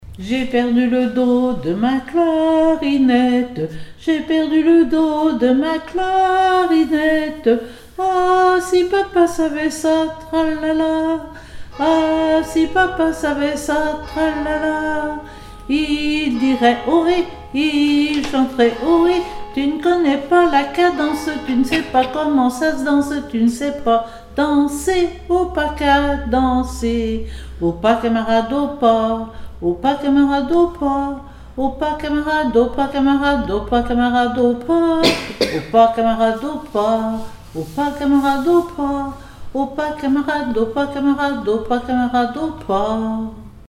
Île-d'Yeu (L')
formulette enfantine : amusette
comptines et formulettes enfantines
Pièce musicale inédite